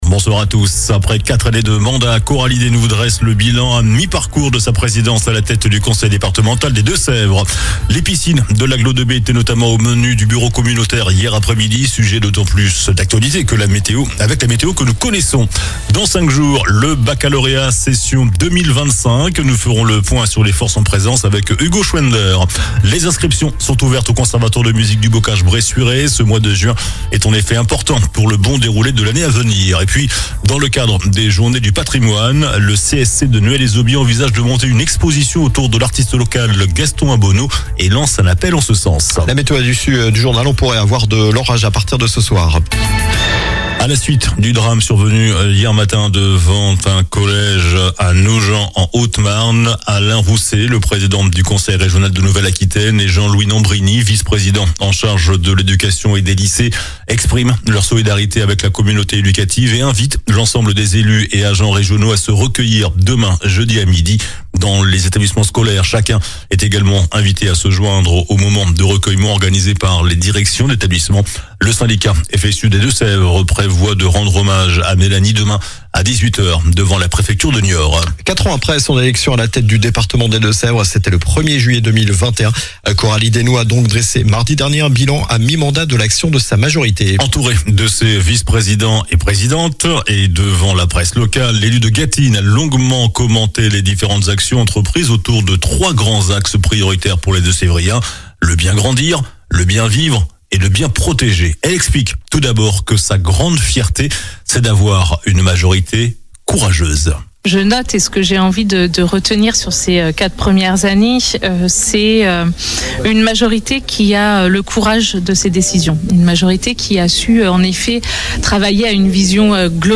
JOURNAL DU MERCREDI 11 JUIN ( SOIR )